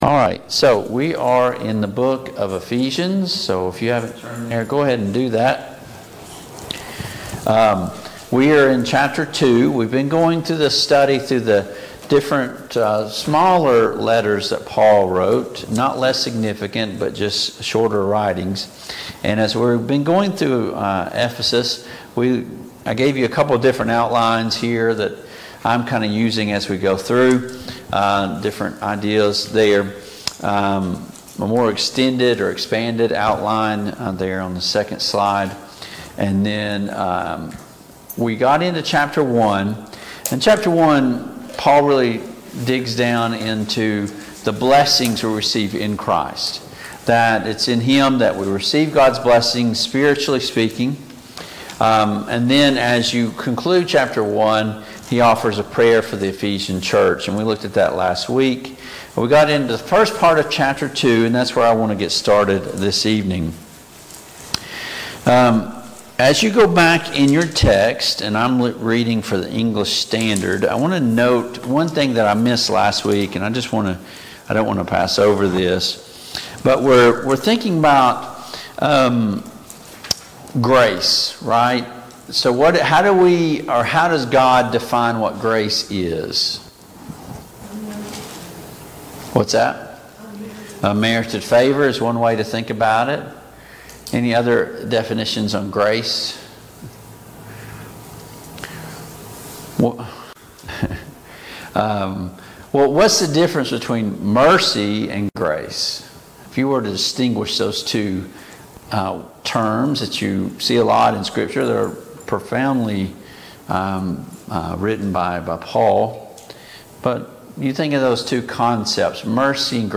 Passage: Ephesians 2:1-22 Service Type: Mid-Week Bible Study